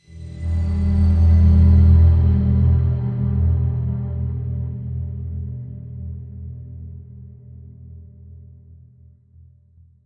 enter-portal.ogg